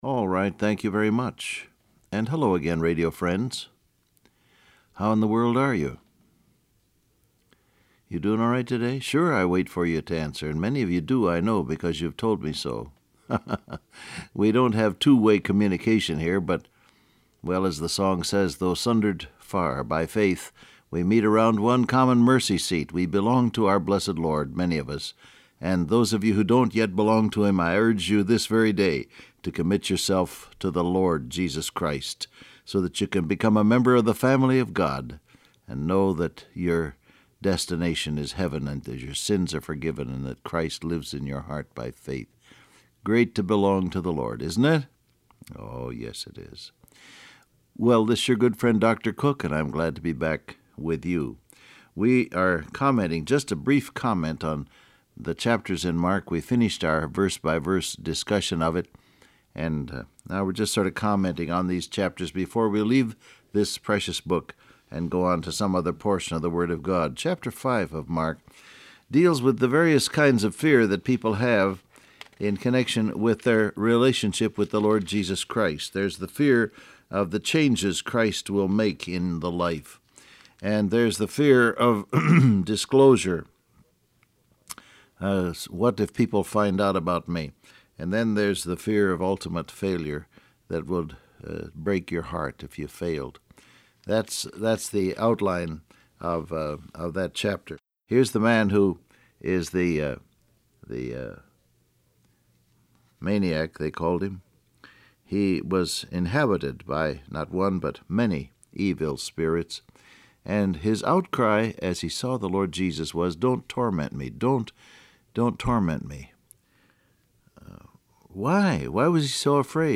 Download Audio Print Broadcast #6632 Scripture: Mark 5 Topics: Sin , Fear , Change , Jesus , Disclosure , Life Transcript Facebook Twitter WhatsApp Alright, thank you very much.